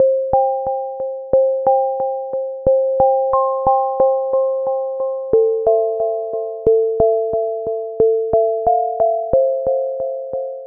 标签： 90 bpm Chill Out Loops Piano Loops 918.79 KB wav Key : Unknown
声道立体声